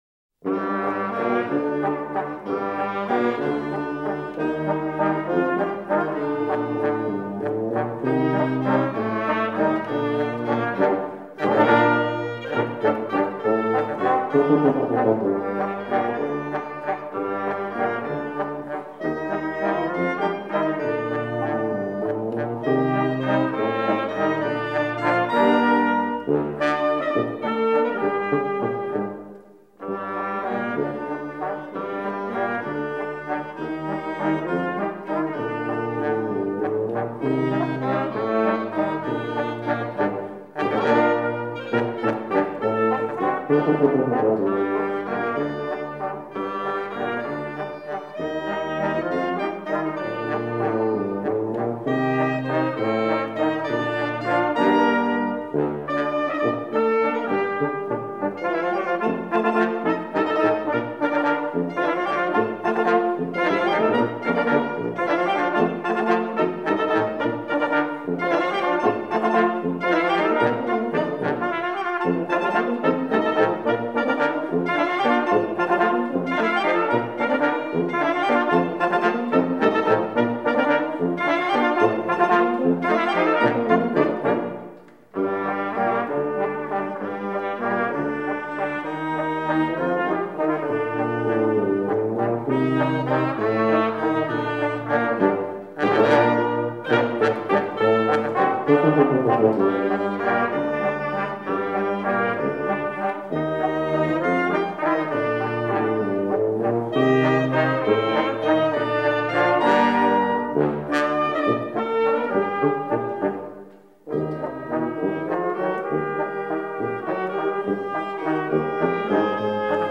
Ticino: Genuine Folk Music from Southern Switzerland
Bandella Tremonese